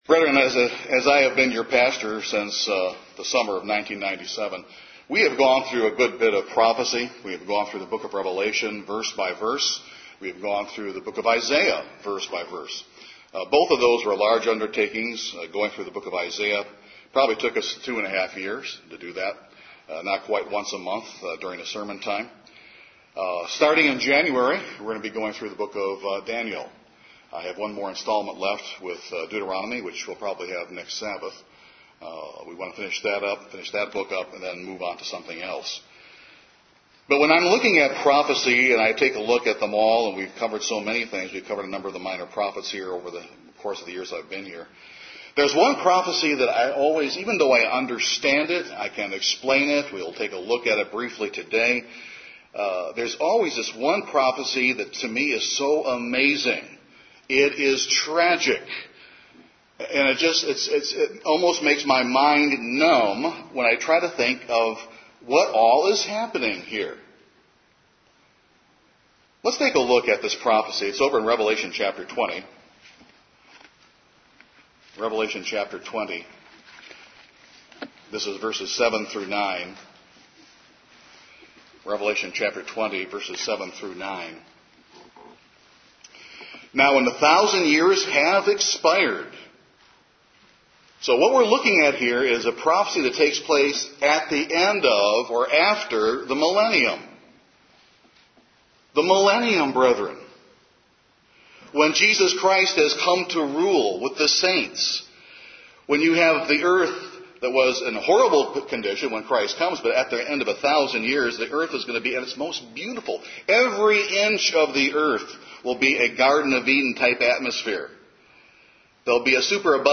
What should you know about spiritual drift and what are some common signs of this deadly spiritual condition? This sermon examines those key thoughts.